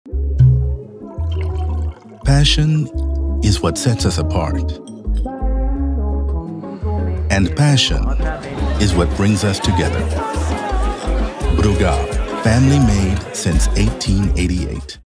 Radio ad
English - USA and Canada
Middle Aged